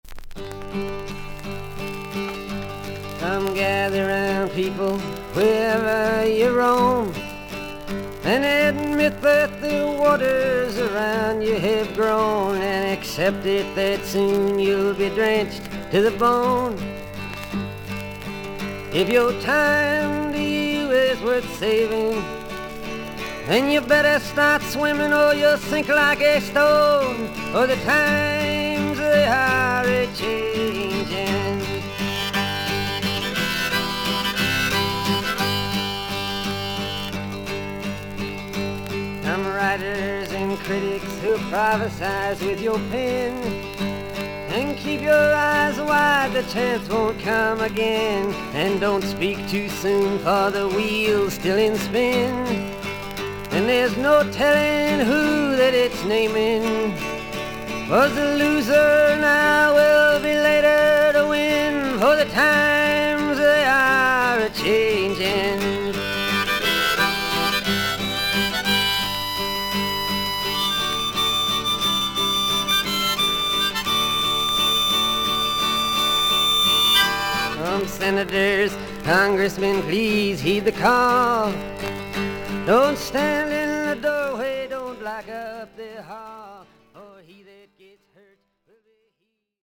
音のグレードはVG+:少々軽いパチノイズの箇所あり。全体的に少々サーフィス・ノイズあり。